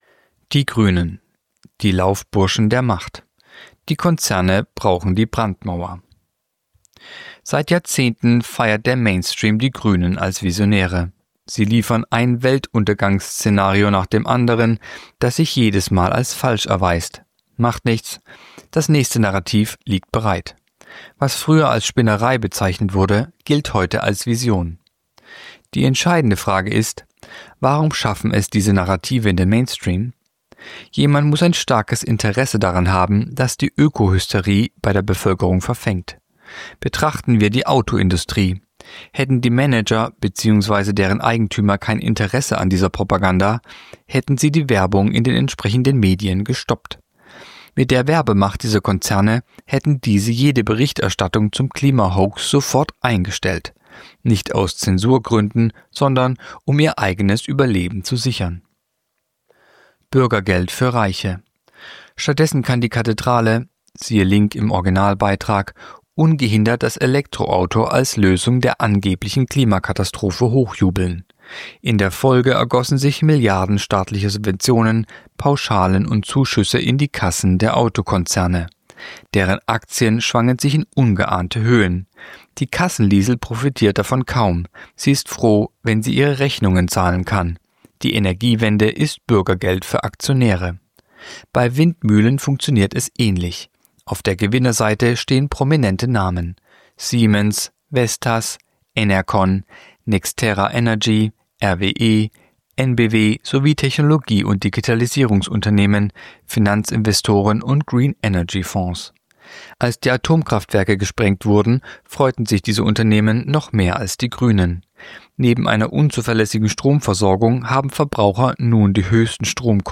Sprecher